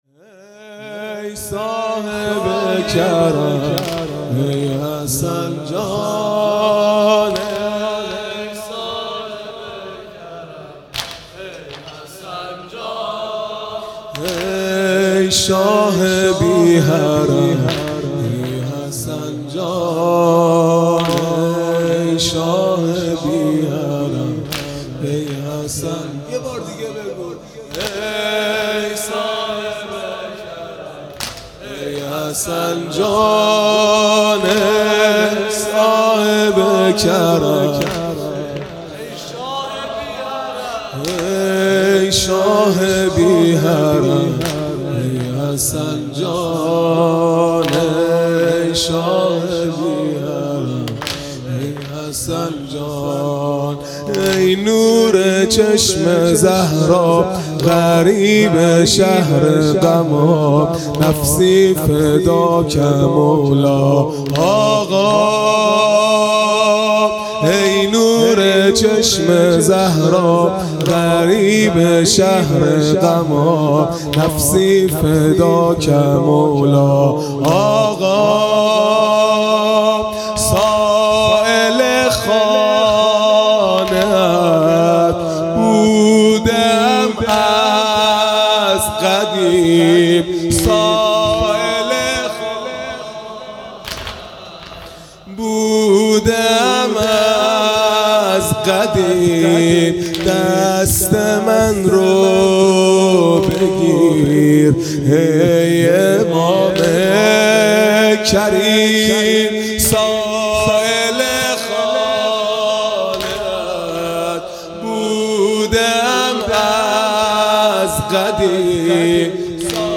عزاداری دهه آخر صفر المظفر (شب دوم)